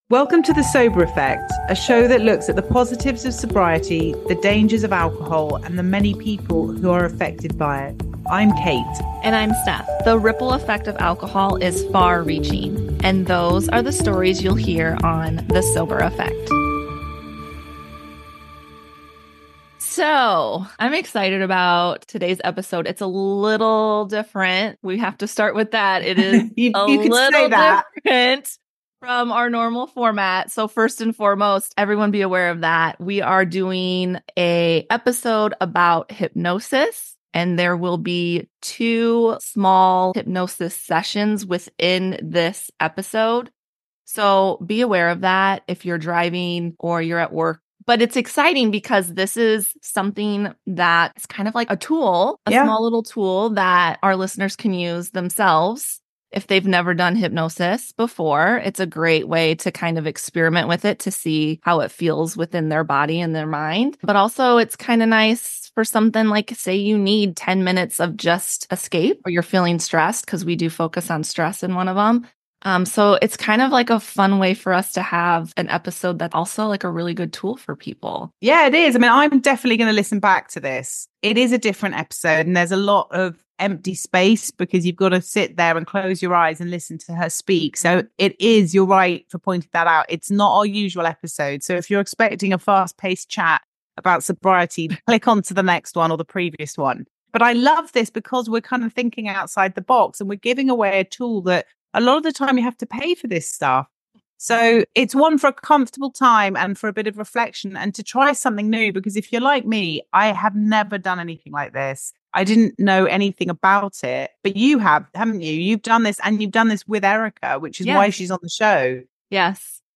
She also provides two hypnosis exercises that you can do in the comfort of your own home, and listen to again and again. With this in mind, you should not listen to this episode while driving!